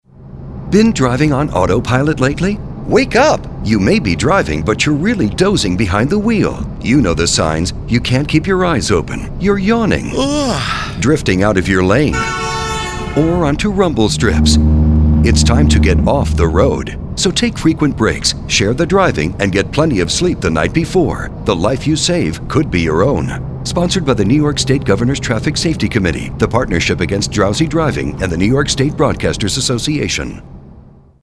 "Wake Up" to the Risks of Drowsy Driving :30 Radio PSA. MP3, 489K (English)
WakeUpRadio.mp3